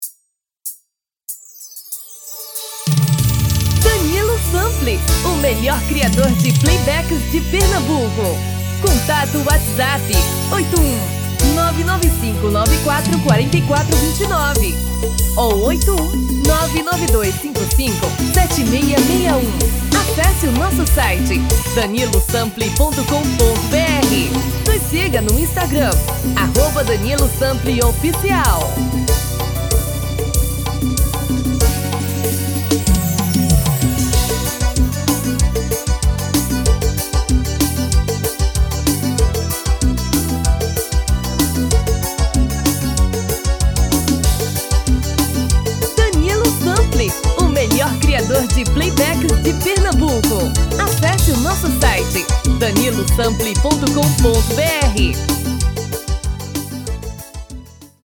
DEMO 1: tom original / DEMO 2: dois tom abaixo